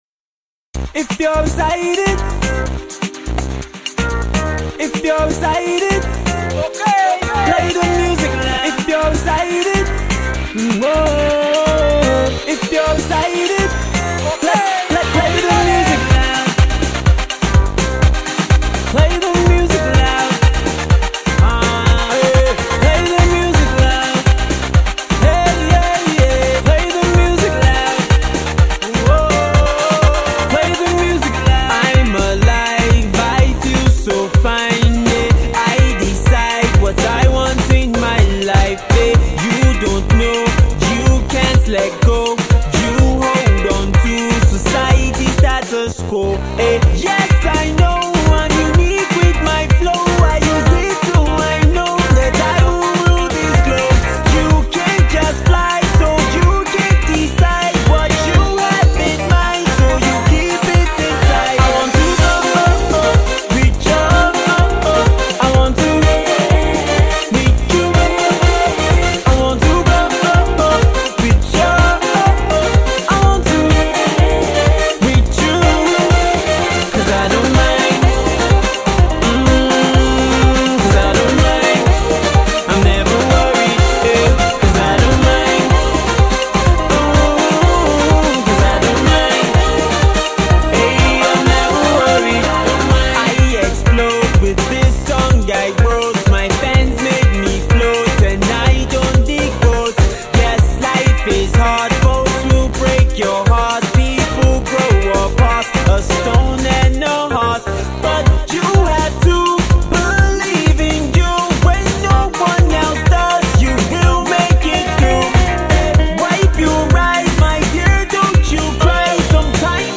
a more upbeat party time groove